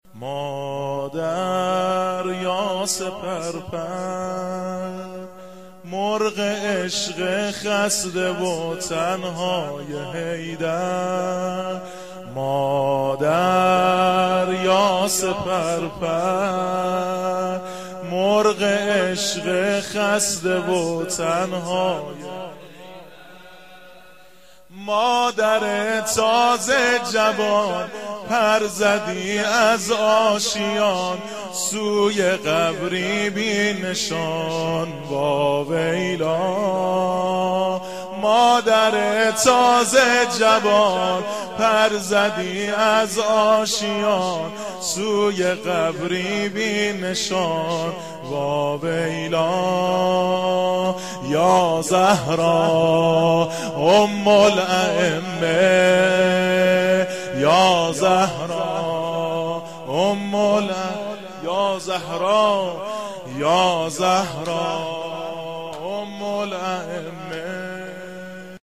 فاطمه الزهرا پس از شهادت نوحه